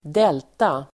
Uttal: [²d'el:ta]